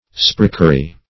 Search Result for " sprechery" : The Collaborative International Dictionary of English v.0.48: Sprechery \Sprech"er*y\ (spr[e^]ch"[~e]r*[y^]), n. [Cf. Gael. spreidh cattle.] Movables of an inferior description; especially, such as have been collected by depredation.